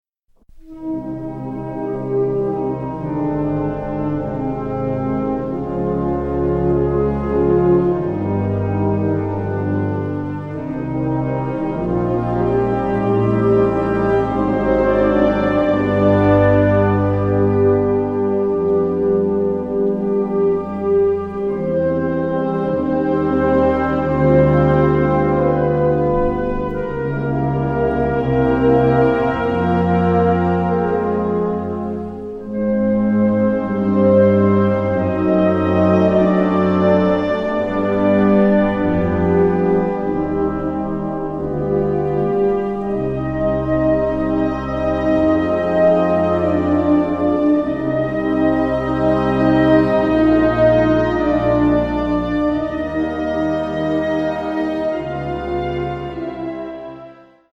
Besetzung: Blasorchester